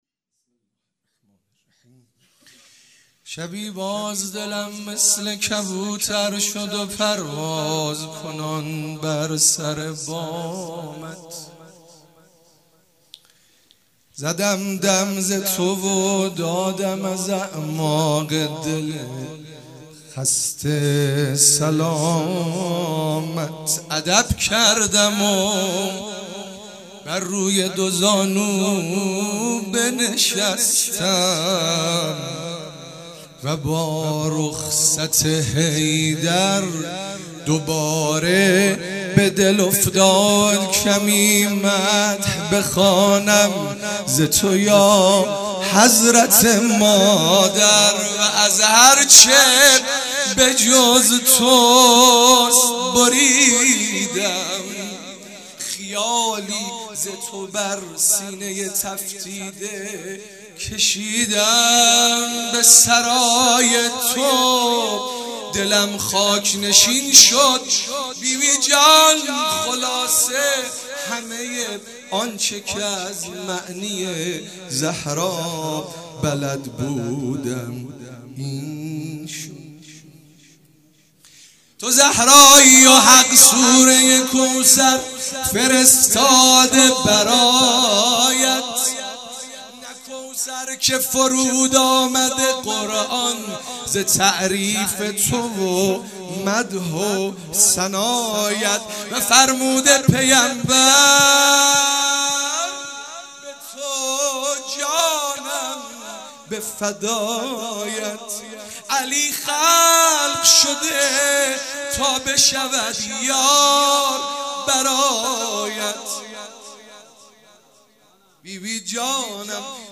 مناسبت : ولادت حضرت فاطمه‌ زهرا سلام‌الله‌علیها
قالب : مدح